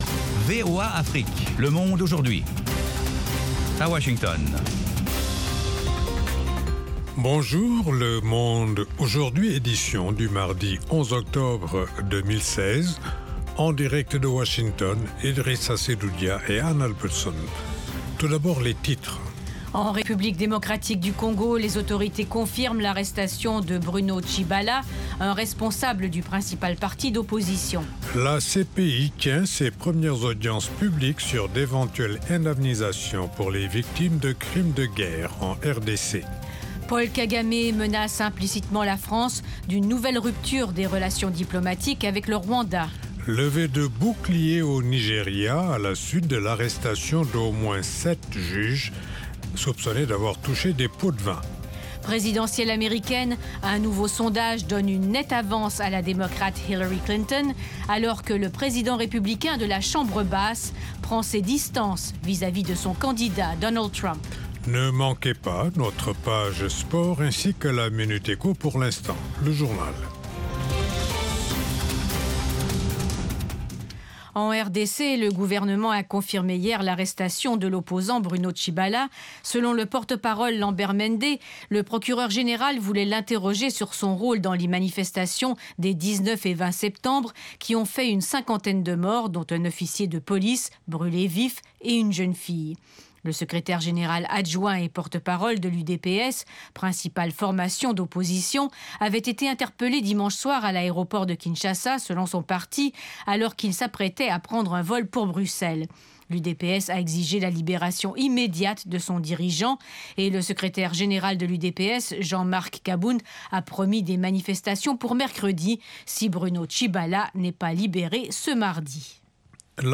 Le Monde Aujourd'hui, édition pour l'Afrique de l'Ouest, une information de proximité pour mieux aborder les préoccupations de nos auditeurs en Afrique de l’Ouest. Toute l’actualité sous-régionale sous la forme de reportages et d’interviews.
Le Monde aujourd'hui, édition pour l'Afrique de l’Ouest, c'est aussi la parole aux auditeurs pour commenter à chaud les sujets qui leur tiennent à coeur.